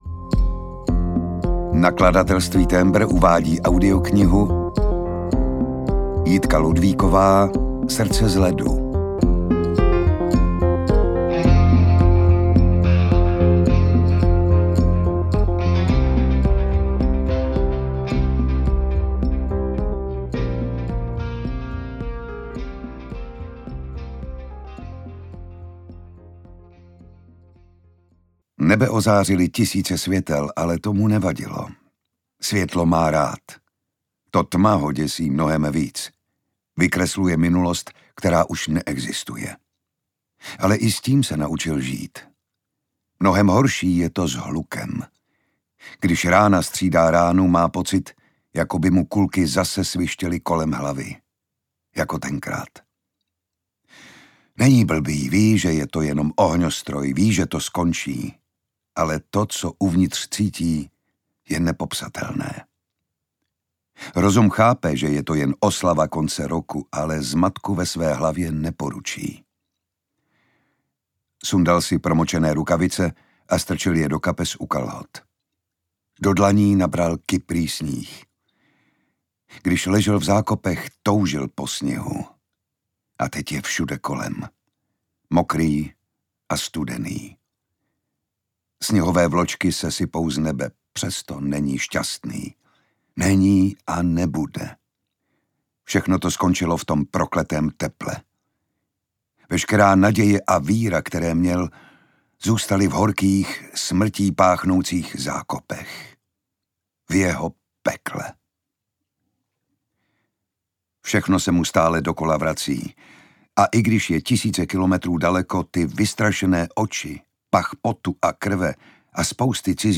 Srdce z ledu audiokniha
Ukázka z knihy
• InterpretJan Šťastný